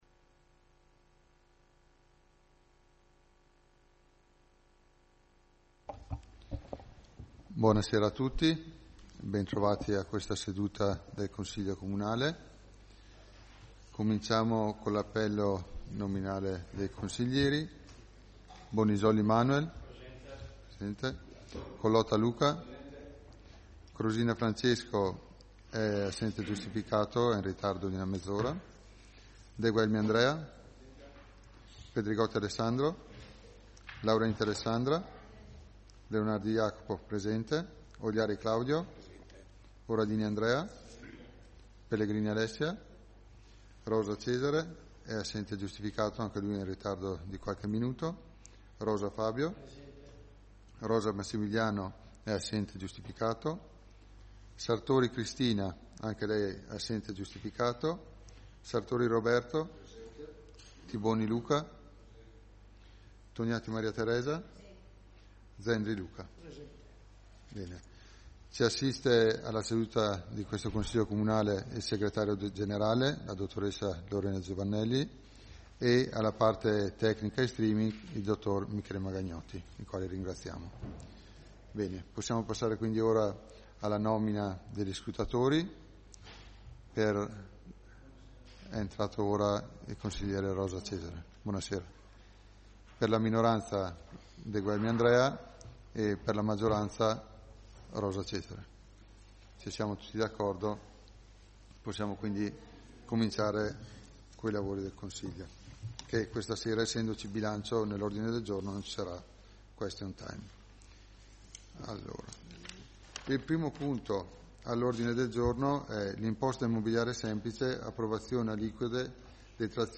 Seduta consiglio comunale del 17 dicembre 2025